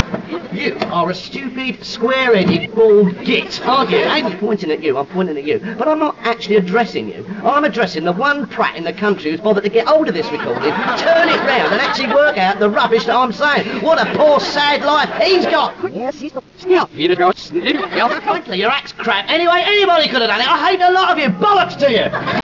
This quote comes from the backwards universe